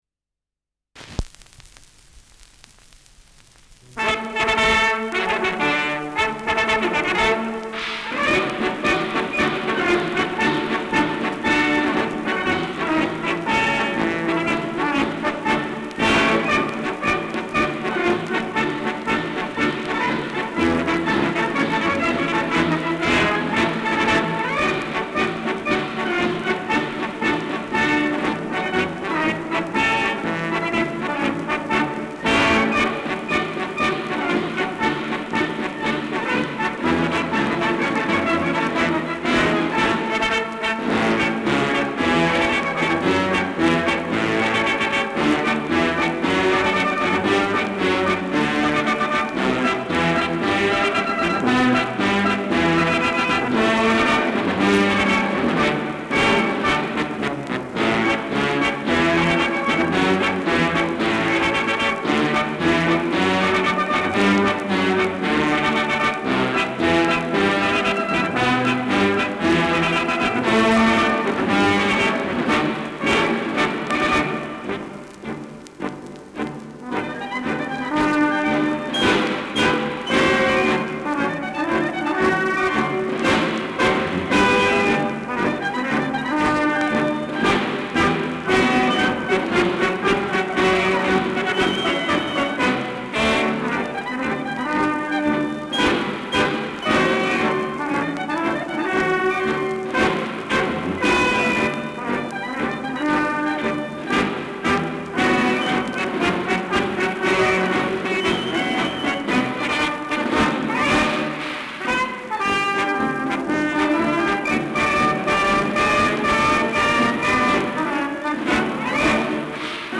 Schellackplatte
[München] (Aufnahmeort)